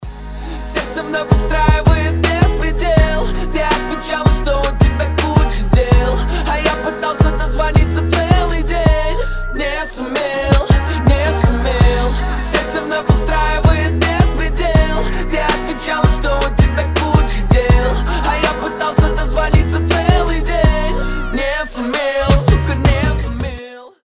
• Качество: 320, Stereo
гитара
мужской голос
Хип-хоп